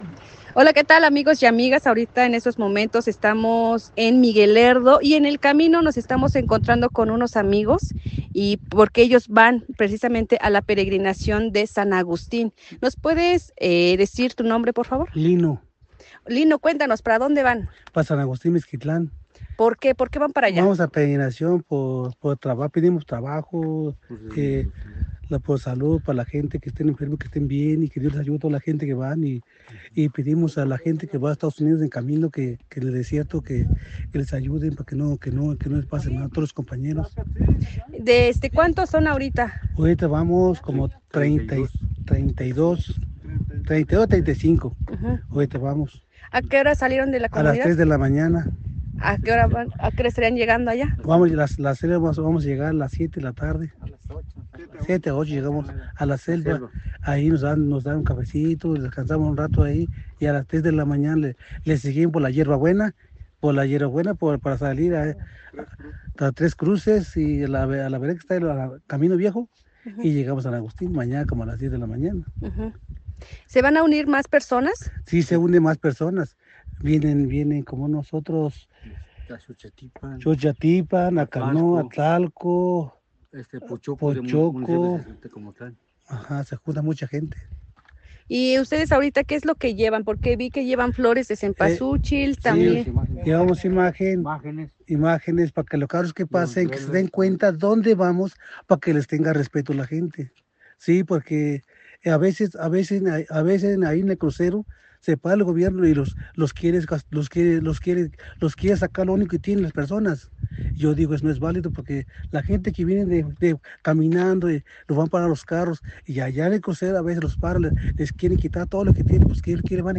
El reporte